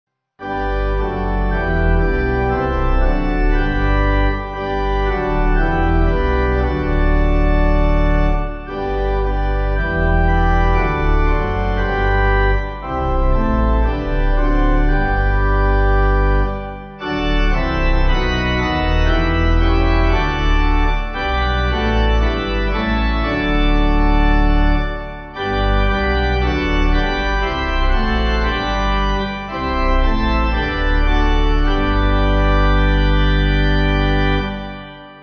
7.6.7.6.D
Organ